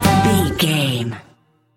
Ionian/Major
acoustic guitar
banjo
bass guitar
drums